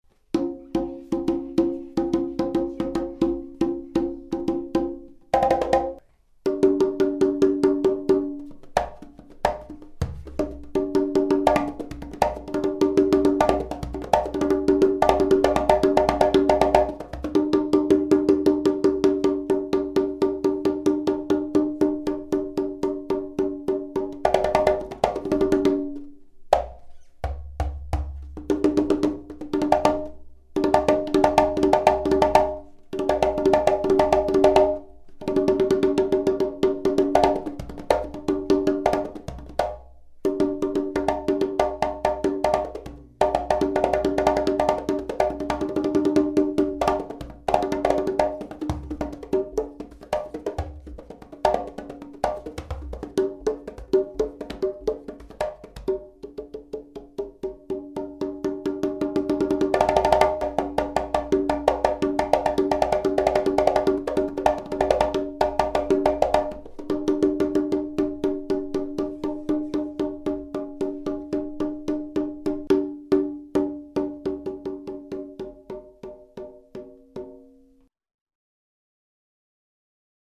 The Conga emits rich, strong and warm clarity with resonance.
Our Quintos produces crackling brilliant highs.
Drum Sound Samples: "Turn Up the Volume !"
Drum Tonality
01. Requinto Conga - 10 1/4 inch drum head